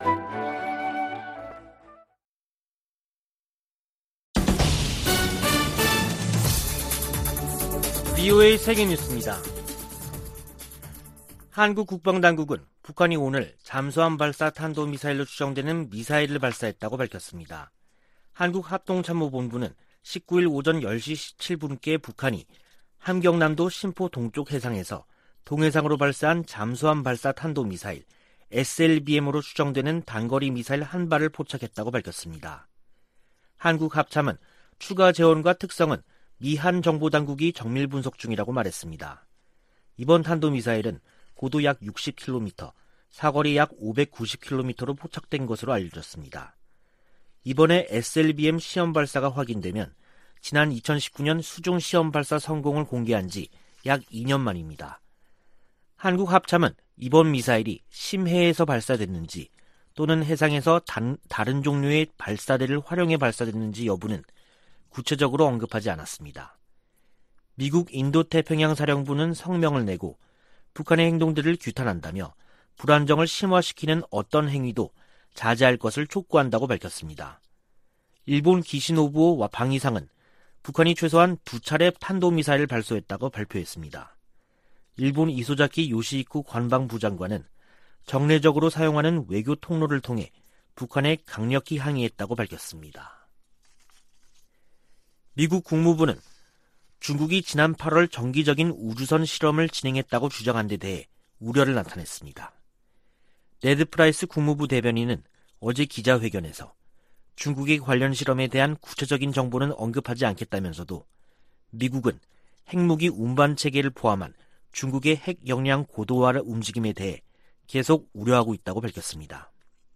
VOA 한국어 간판 뉴스 프로그램 '뉴스 투데이', 2021년 10월 19일 3부 방송입니다. 한국 합동참모본부는 북한이 동해상으로 잠수함발사 탄도미사일(SLBM)로 추정되는 단거리 미사일 1발을 발사했음을 포착했다고 밝혔습니다. 미국과 한국, 일본 정보수장이 서울에서 만나 단거리 미사일 발사 등 북한 문제를 협의했습니다.